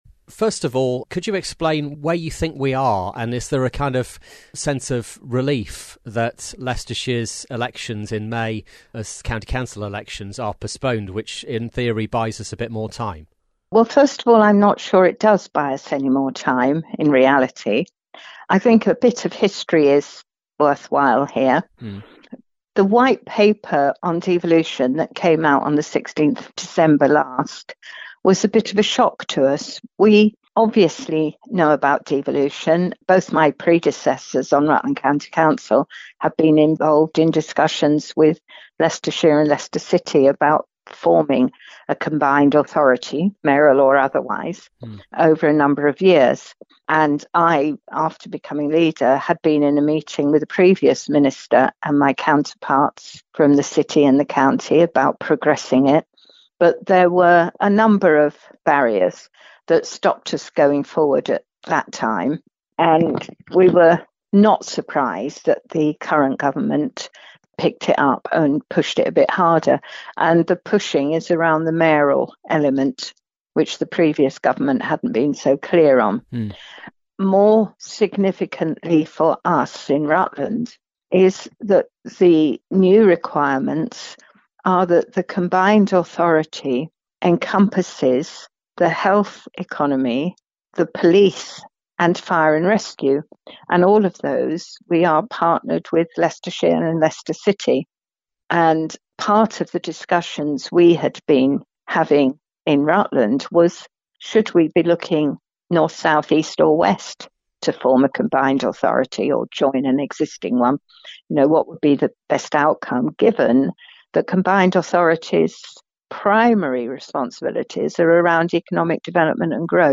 Below is the full interview with RCC leader Councillor Gale Waller.